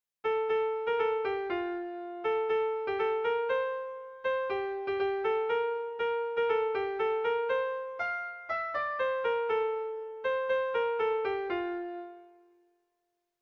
Bertso melodies - View details   To know more about this section
Sentimenduzkoa
ABD